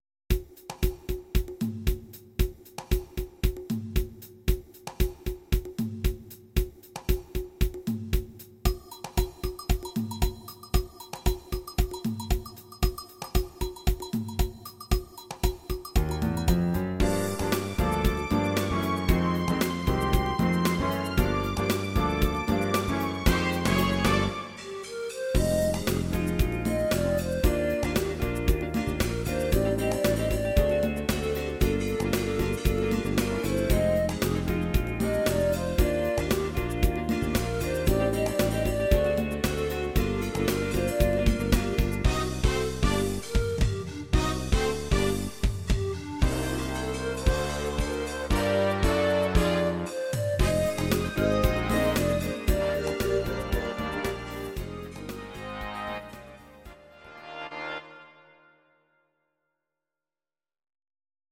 Audio Recordings based on Midi-files
Pop, Disco, 1970s